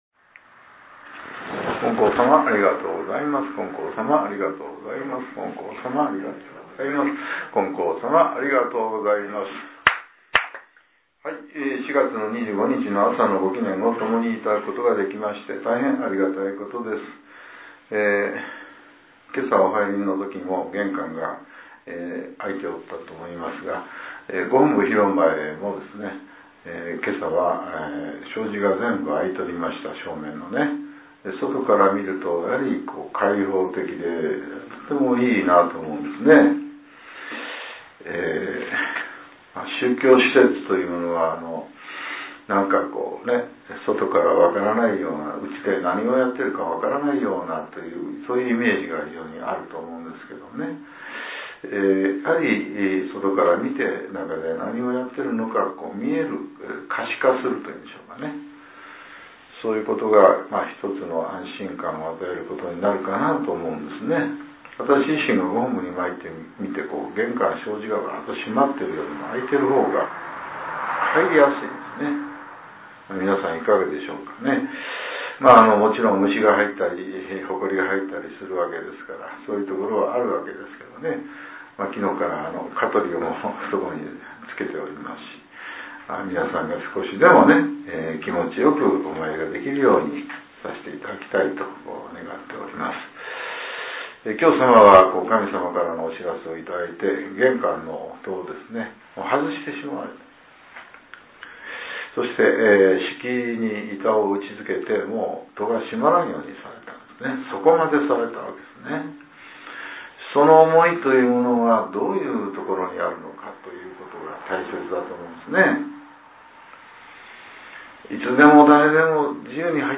令和７年４月２５日（朝）のお話が、音声ブログとして更新されています。